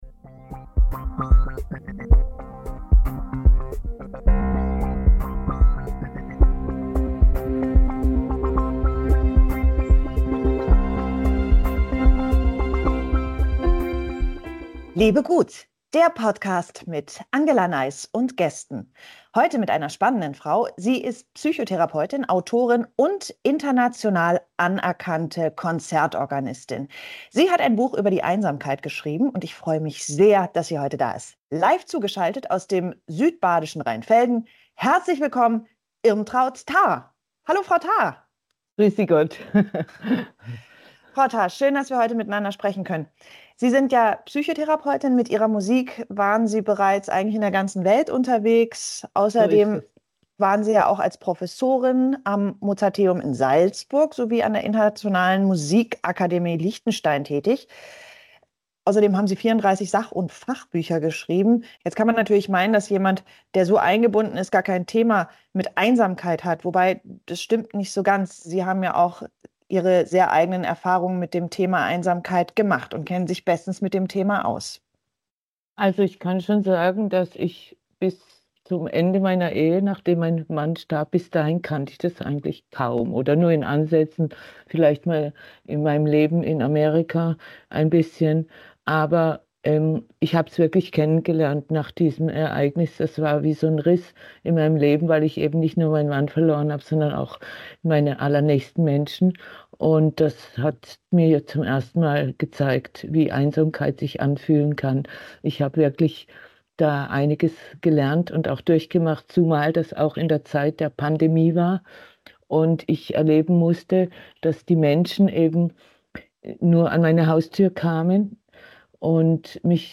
Ein humorvolles, nachdenkliches und ermutigendes Gespräch also.